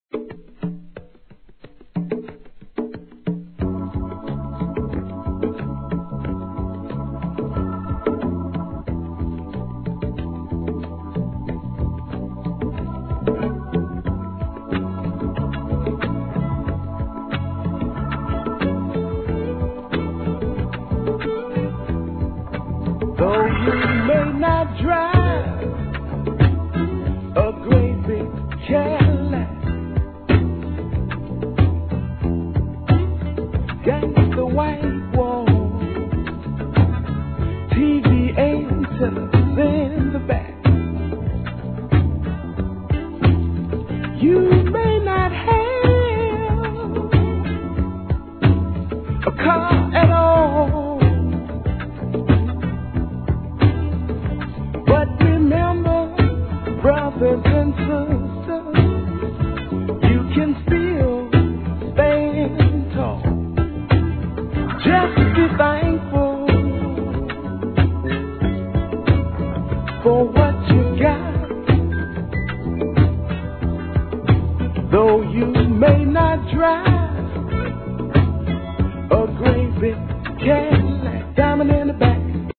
¥ 1,320 税込 関連カテゴリ SOUL/FUNK/etc...